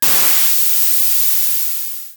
GasReleasing13.wav